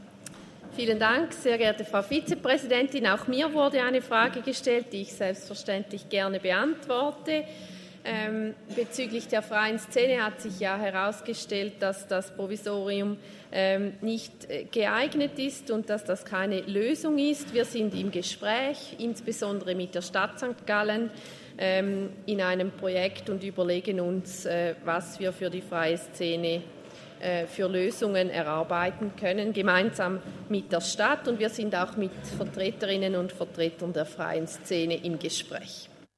20.9.2023Wortmeldung